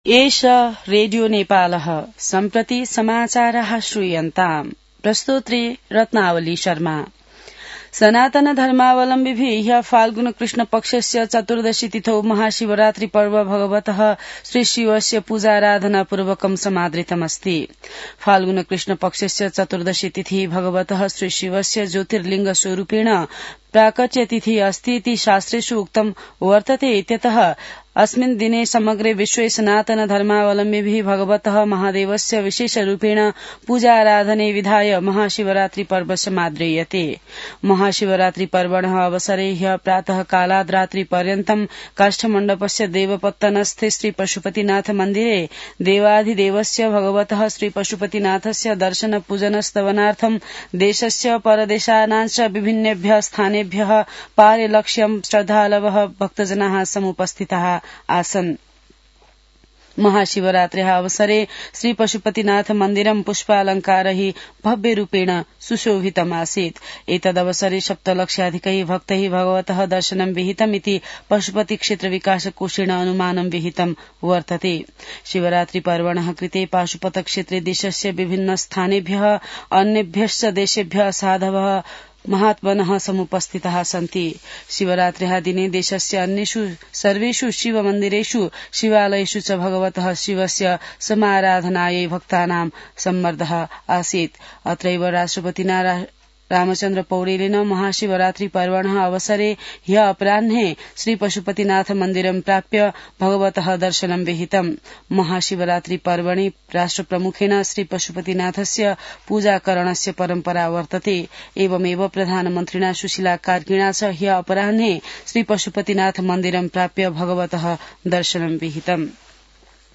संस्कृत समाचार : ४ फागुन , २०८२